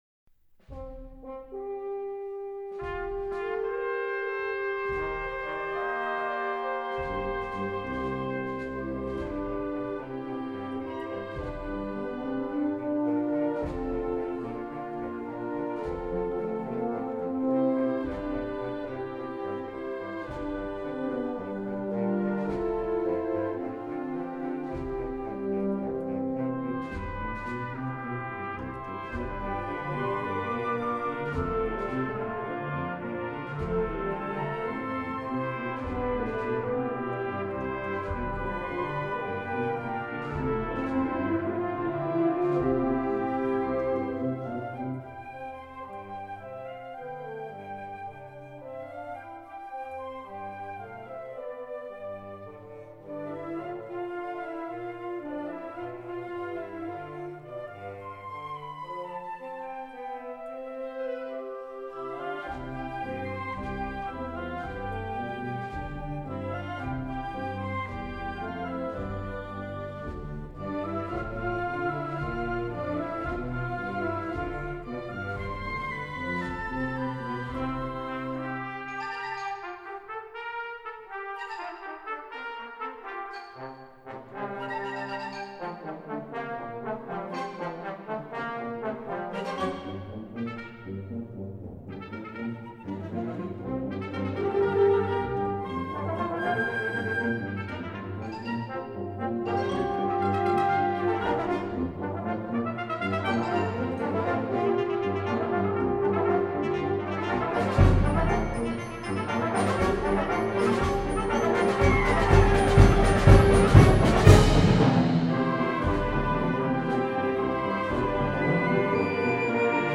Genre: Band